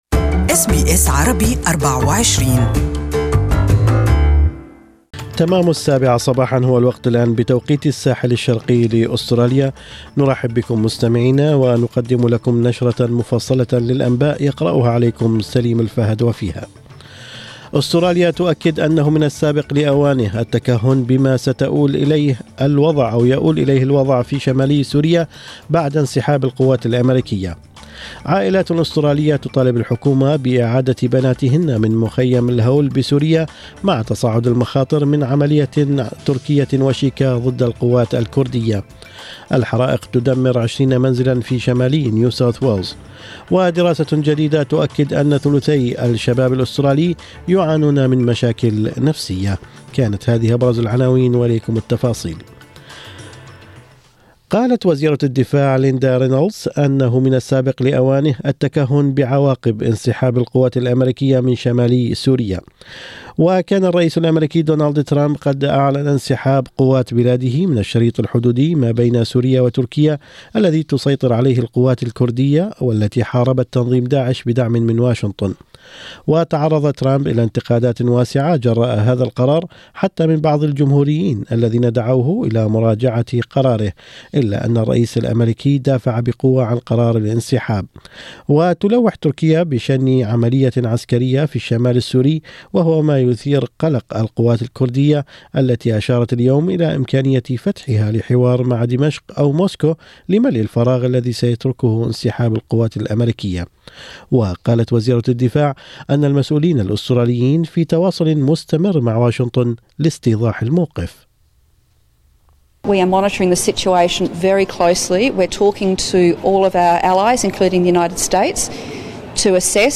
Morning News: 40% young Australians negatively impacted by social media